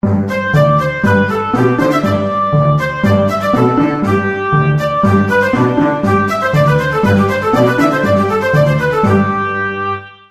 Textura monódica sobre obstinato. Ejemplo.
monódico